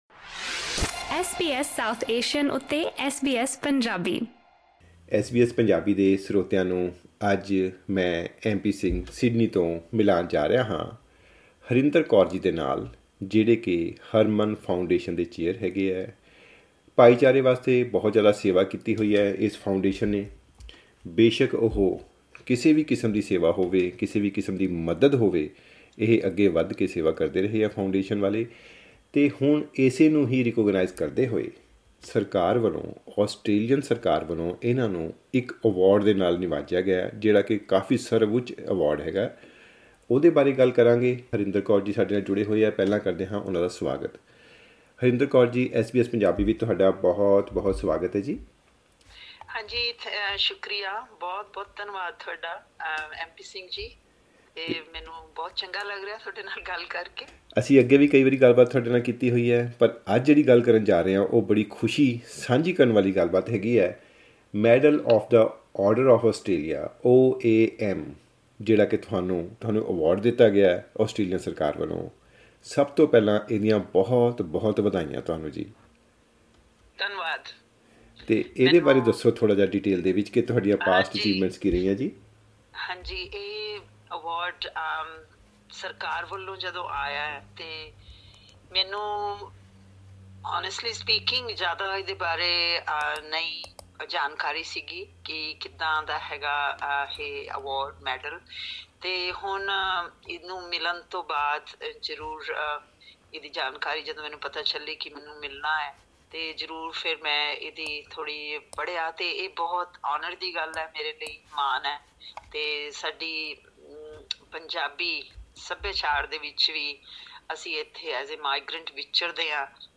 ਐਸ ਬੀ ਐਸ ਪੰਜਾਬੀ ਨਾਲ ਗੱਲ ਕਰਦੇ ਹੋਏ